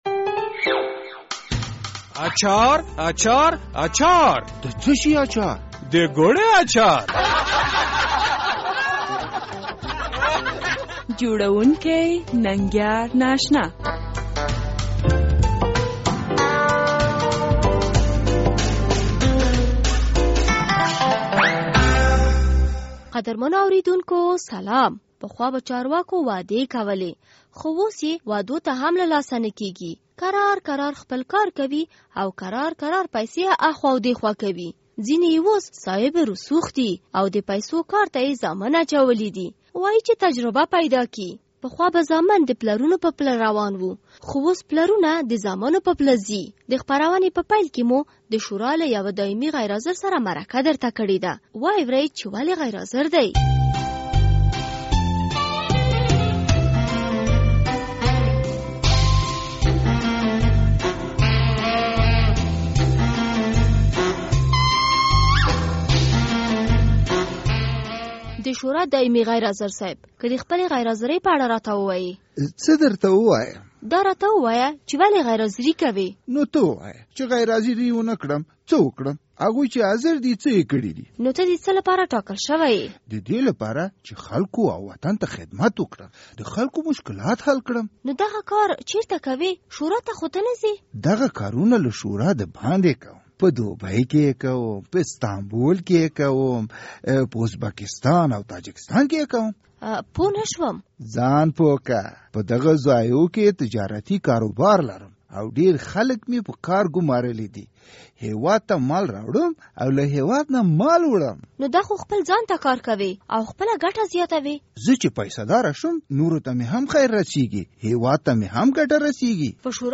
د ګوړې اچار: د پارلمان دایمي غیر حاضر سره مرکه